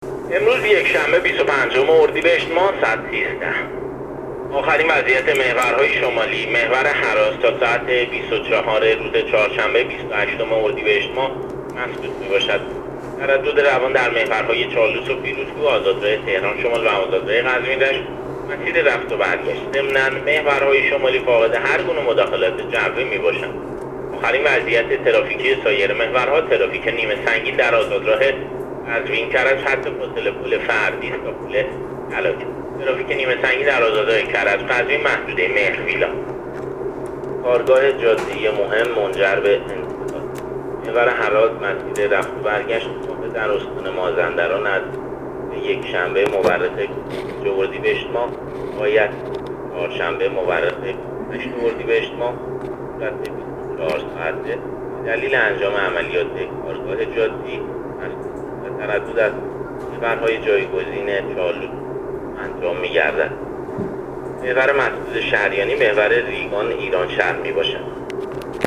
گزارش رادیو اینترنتی از آخرین وضعیت ترافیکی جاده‌ها تا ساعت ۱۳ بیست و پنجم اردیبهشت؛